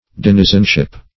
Denizenship \Den"i*zen*ship\, n. State of being a denizen.